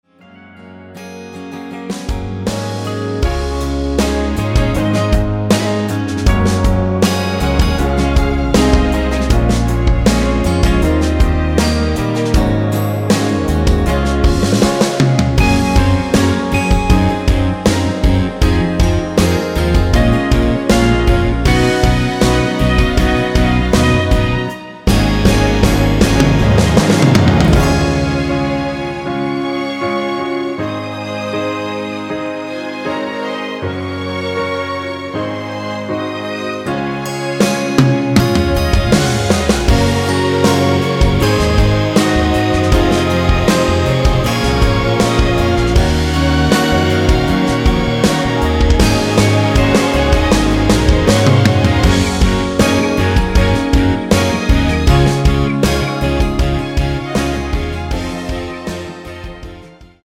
원키에서(+2)올린 편집 MR입니다.(미리듣기 참조)
Db
앞부분30초, 뒷부분30초씩 편집해서 올려 드리고 있습니다.
중간에 음이 끈어지고 다시 나오는 이유는